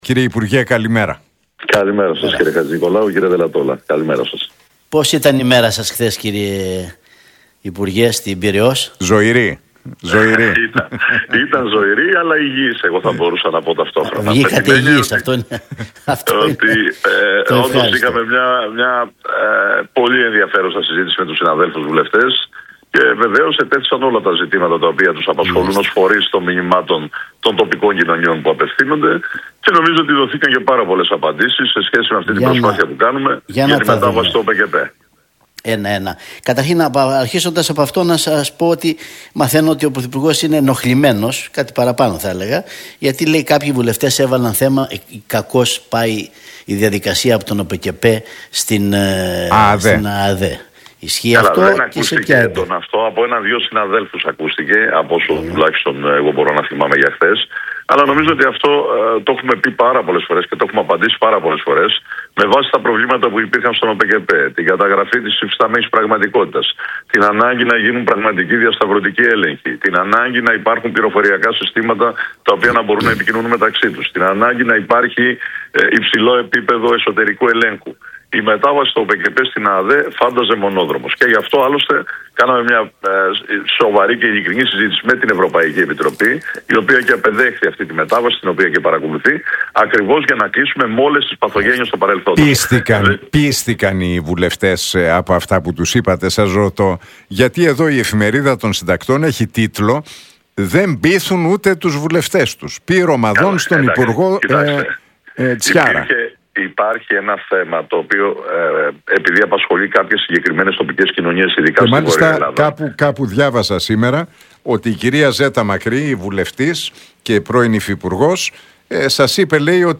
Ο υπουργός Αγροτικής Ανάπτυξης και Τροφίμων, Κώστας Τσιάρας, σε συνέντευξή του στον Realfm 97,8, αναφέρθηκε στις αγροτικές κινητοποιήσεις και τις πληρωμές σε